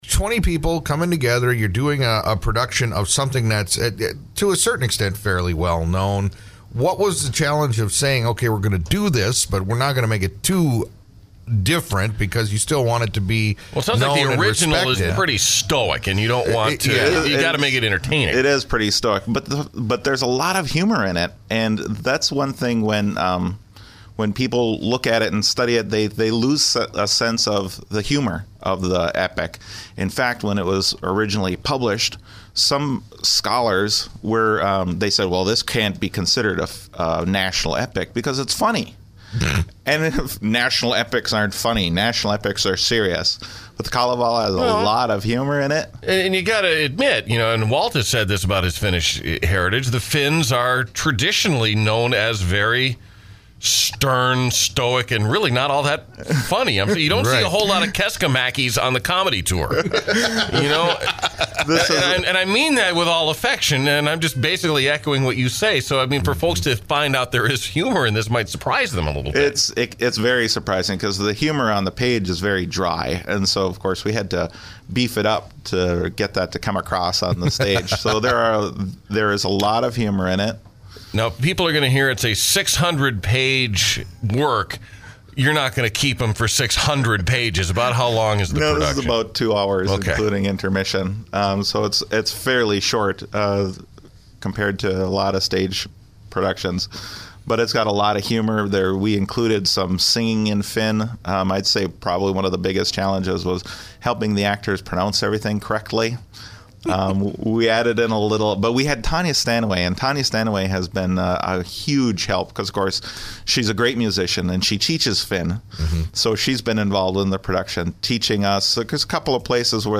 Old Interviews Archive